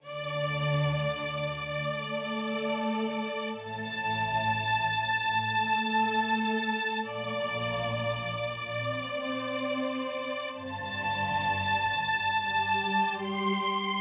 VTS1 Universe Kit 137BPM Choirstrings DRY.wav